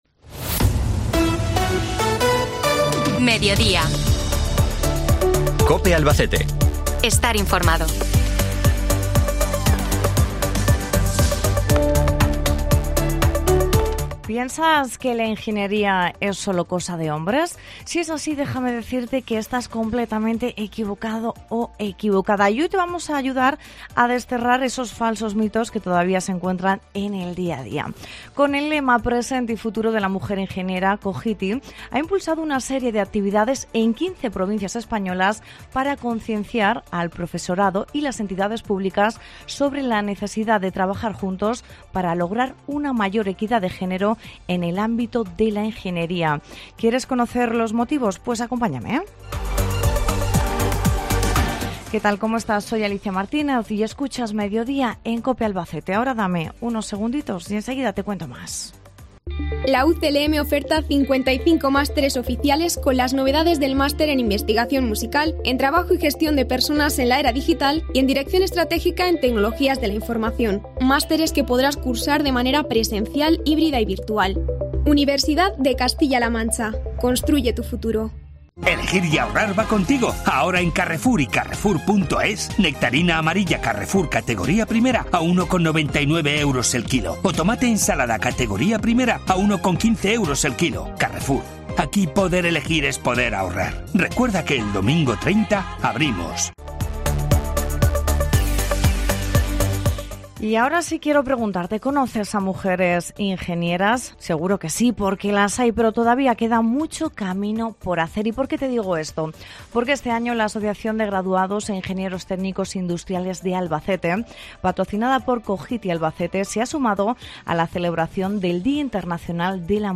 Con este motivo, se han realizado entrevistas a cuatro mujeres, tres ingenieras y una estudiante de grado, para conocer sus trayectorias y perspectivas sobre la importancia de la mujer en la ingeniería.